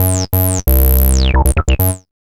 1708L B-LOOP.wav